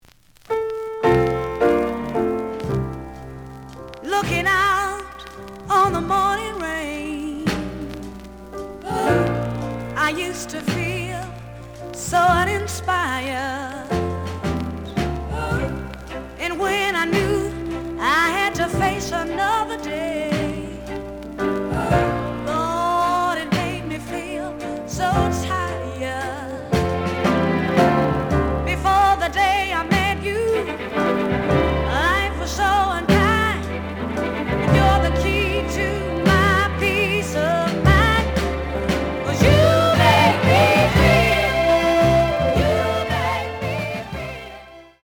The audio sample is recorded from the actual item.
●Genre: Soul, 60's Soul
Slight edge warp.